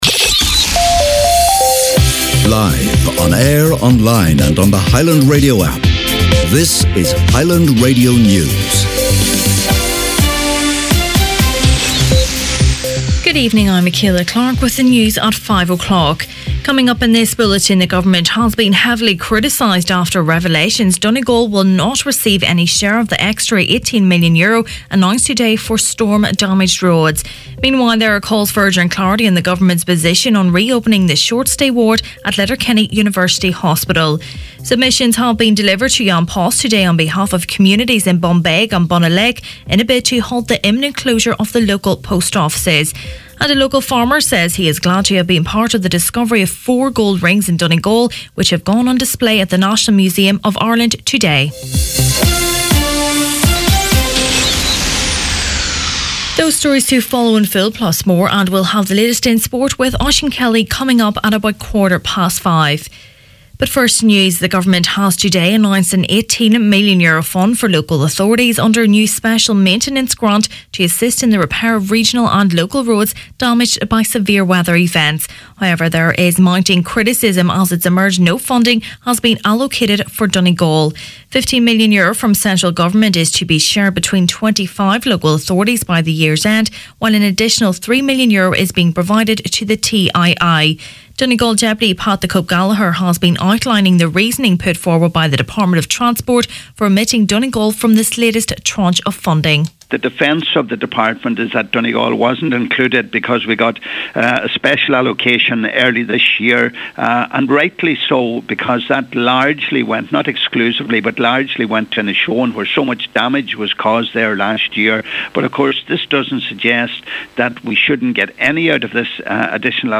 Main Evening News, Sport and Obituaries Wednesday October 24th